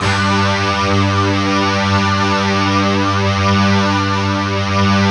Index of /90_sSampleCDs/Optical Media International - Sonic Images Library/SI1_DistortGuitr/SI1_DstGtrWalls